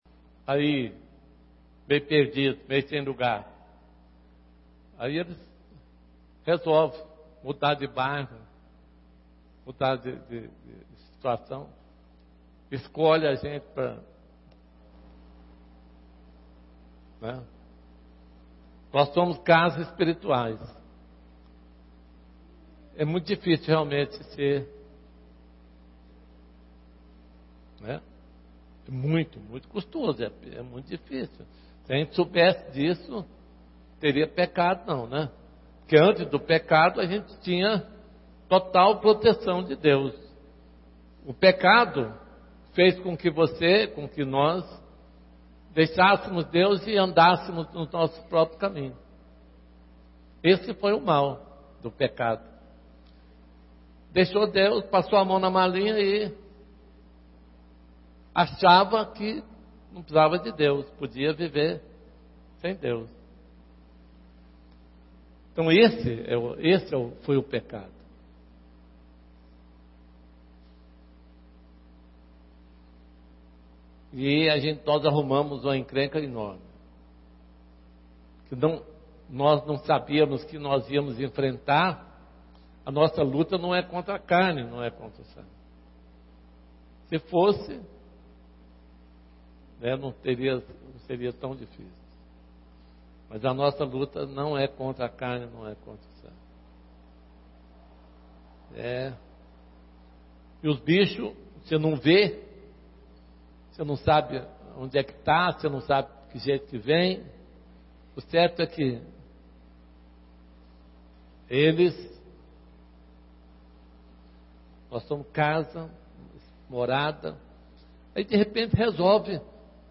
Domingo manhã